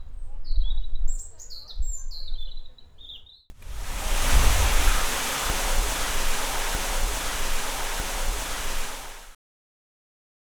soundscape Lodz.wav